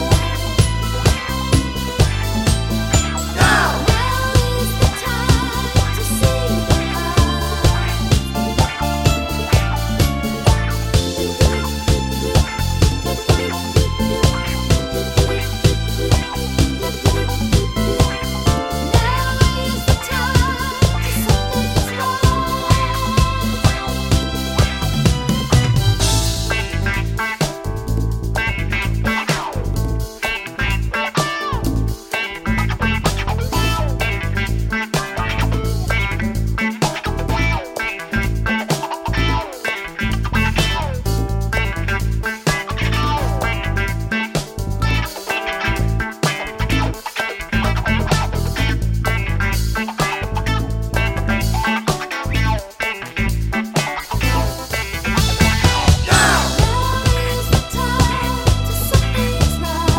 no Backing Vocals Soul / Motown 3:50 Buy £1.50